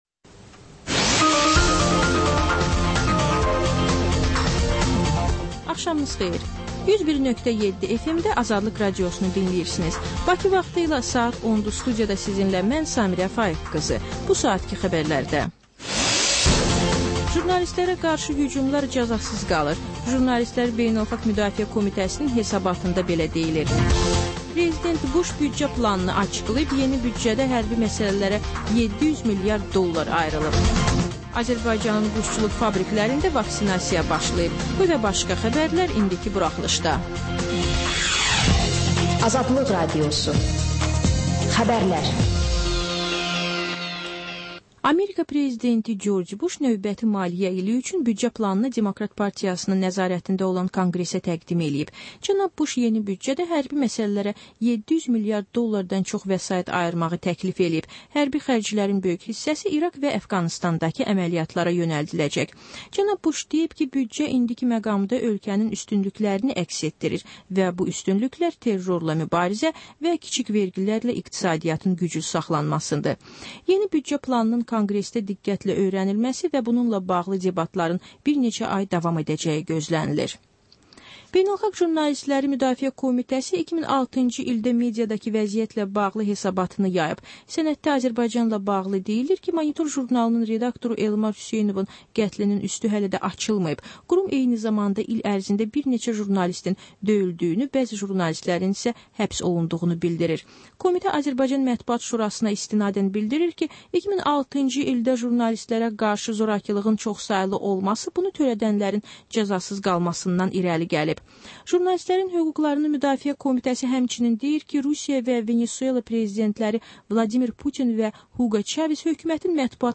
Xəbərlər, reportajlar, müsahibələr. Və sonda: Azərbaycan Şəkilləri: Rayonlardan reportajlar.